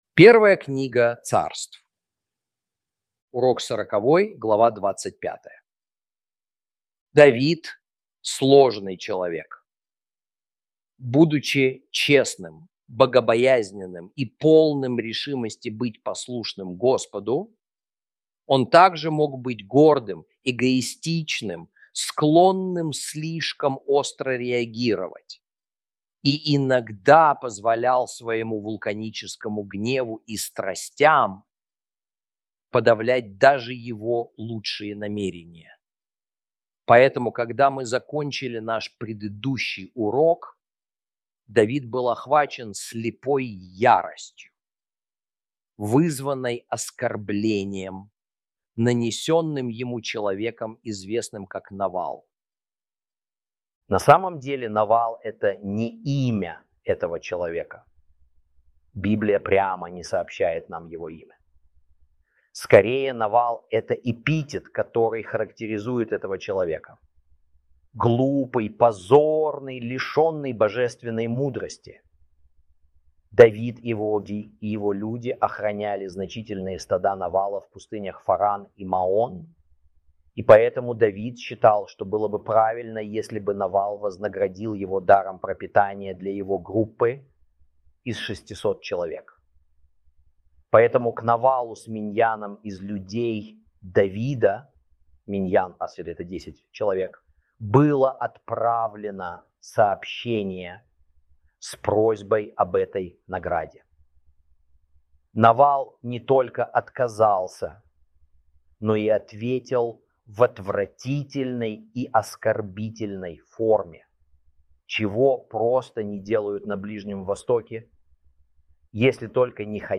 ru-audio-1-samuel-lesson-40-ch25.mp3